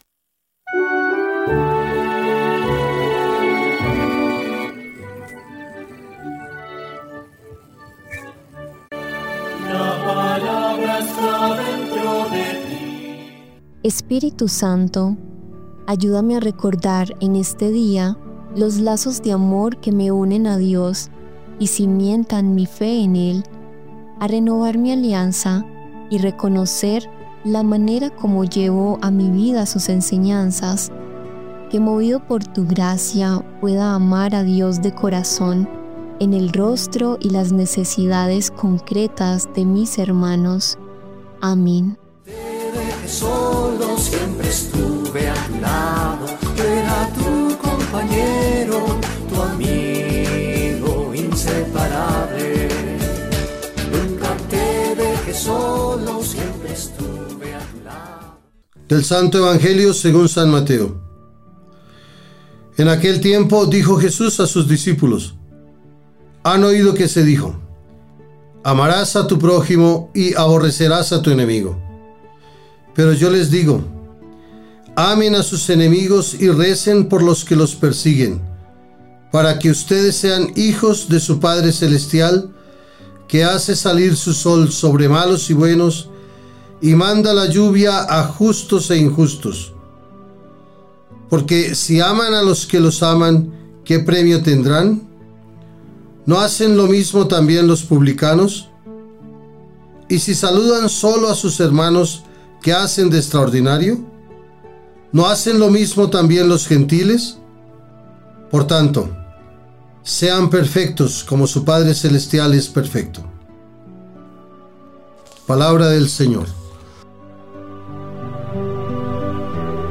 Lectura del libro de la Sabiduría 2, 1a. 12-22